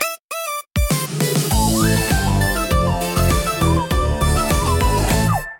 funny
当サイトの音素材は、Suno（有料プラン）または Sora（Sora 2）を利用して制作しています。